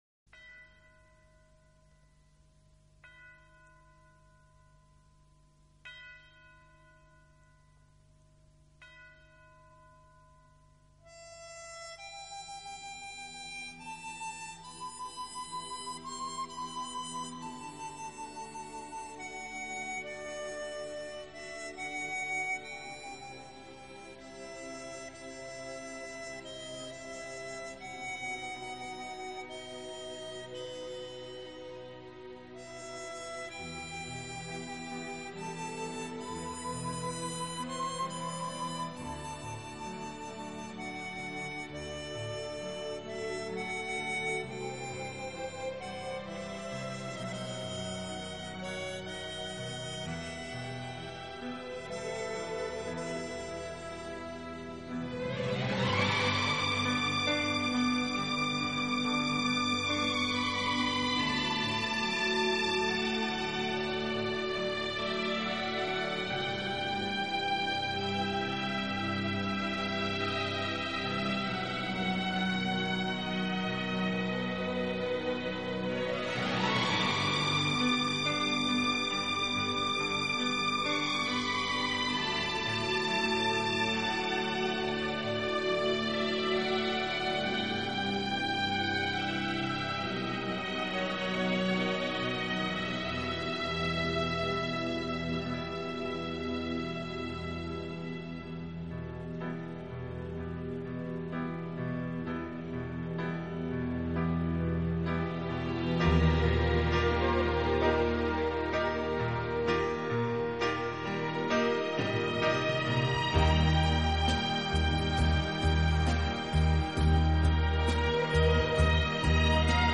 顶级轻音乐